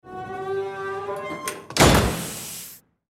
Звуки лаборатории
Здесь собраны реалистичные аудиоэффекты: от тихого гудения оборудования до звонких перекликов стеклянных колб.
Дверь захлопнулась